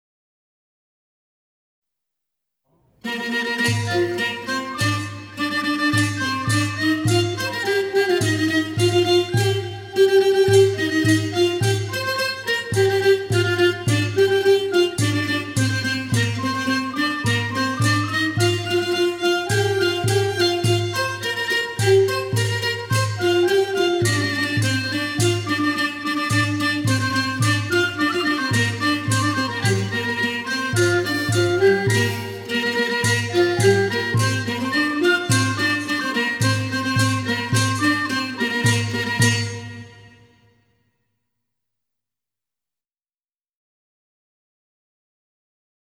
เพลงมหาฤกษ์ (วงเครื่องสาย)